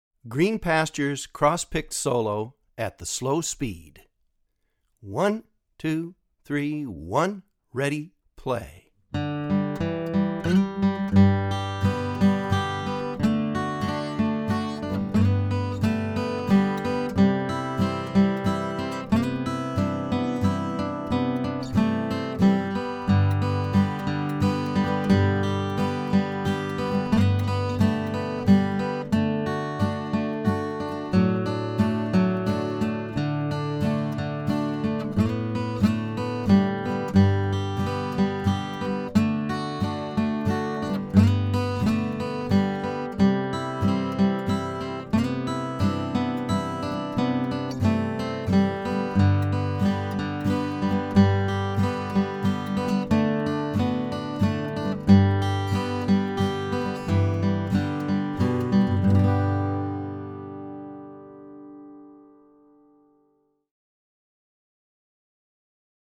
DIGITAL SHEET MUSIC - FLATPICK GUITAR SOLO
Online Audio (both slow and regular speed)